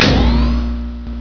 I've done some code for this game and created most of the sound effects used on it.